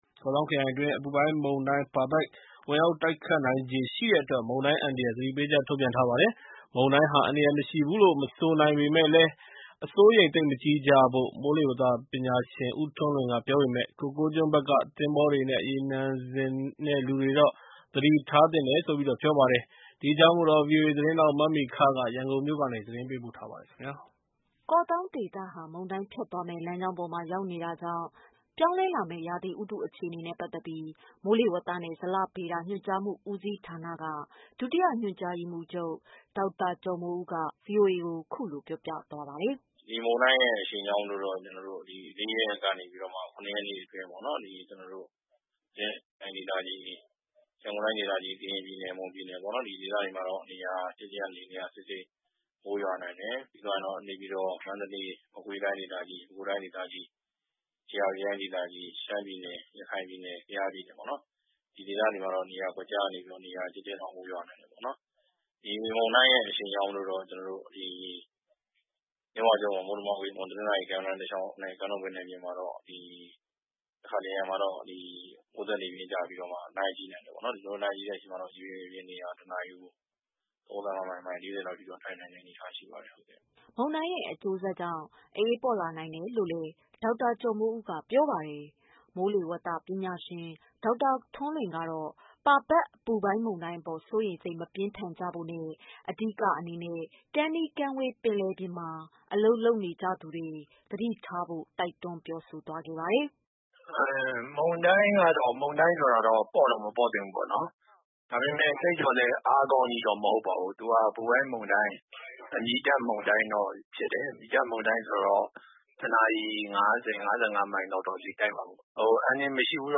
ရန်ကုန်မြို့ကနေ သတင်းပေးပို့ထားပါ တယ်။